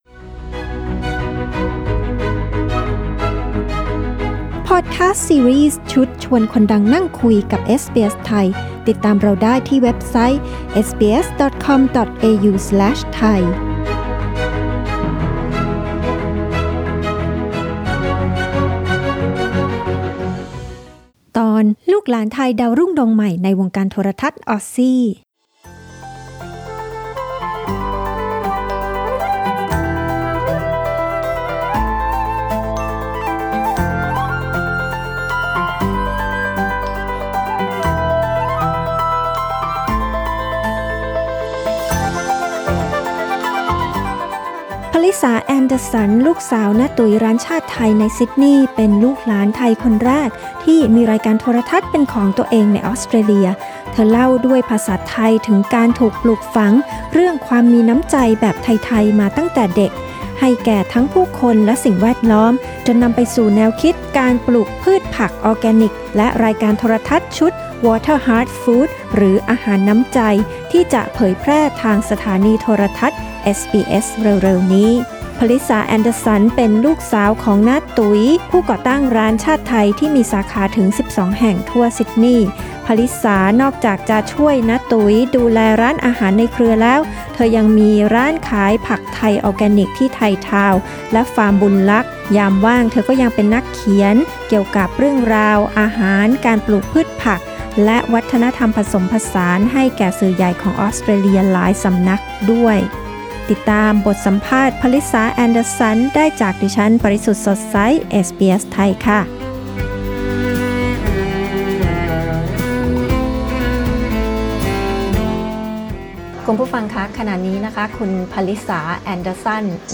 เธอเล่าด้วยภาษาไทยถึงการถูกปลูกฝังมาตั้งแต่เด็กเรื่องความมีน้ำใจให้แก่ทั้งผู้คนและสิ่งแวดล้อม บทสัมภาษณ์นี้เผยแพร่ในรายการเอสบีเอส ไทย ครั้งแรกเมื่อ 26 พ.ย. 2020